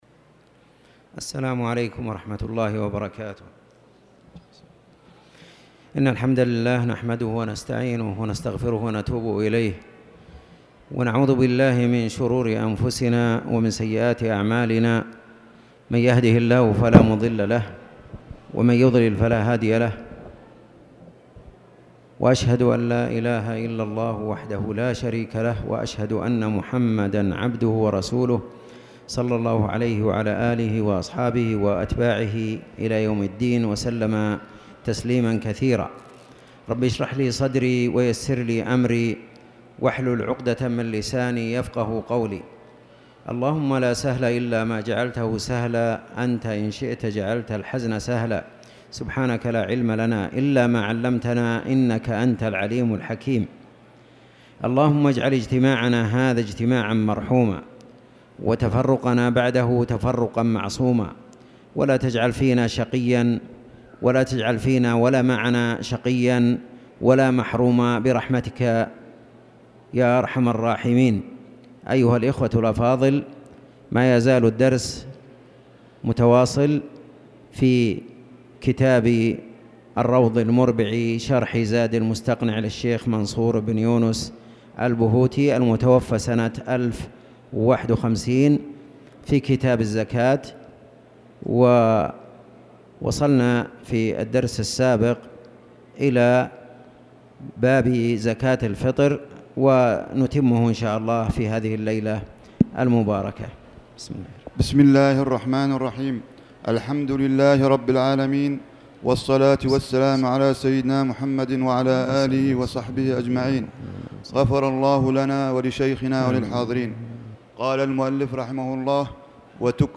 تاريخ النشر ٢٤ محرم ١٤٣٨ هـ المكان: المسجد الحرام الشيخ